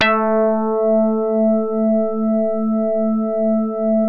JAZZ HARD A2.wav